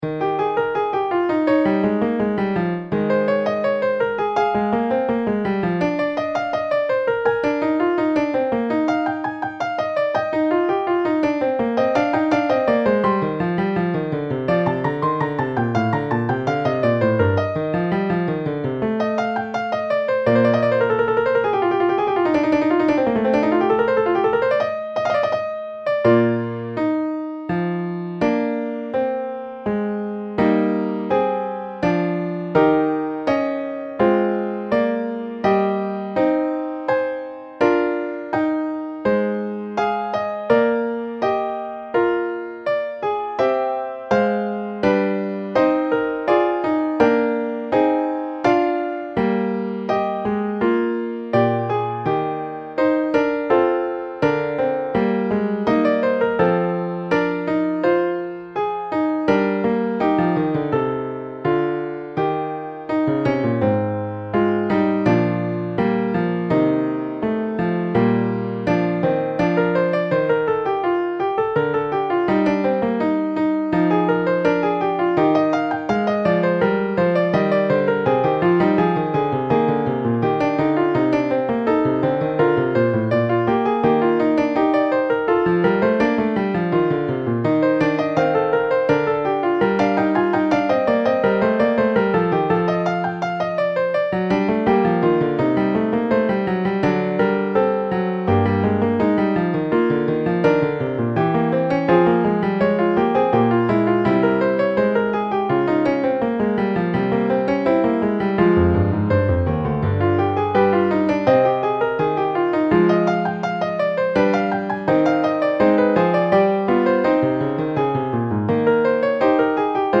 Prelude in E Flat Major.mp3